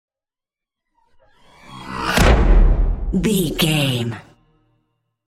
Sci fi whoosh to hit 769
Sound Effects
dark
futuristic
intense
tension
woosh to hit